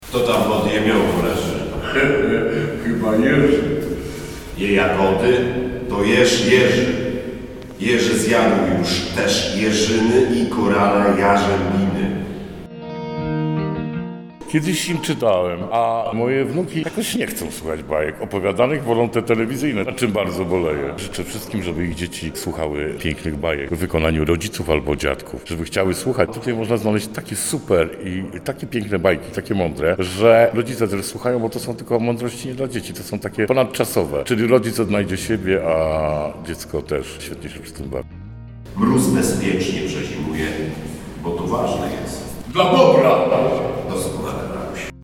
Performatywne czytanie przypomniało o pięknie tradycji spędzania czasu z pociechami przed snem.
W niedzielny poranek usłyszeliśmy fragmenty pracy w wykonaniu aktorskim.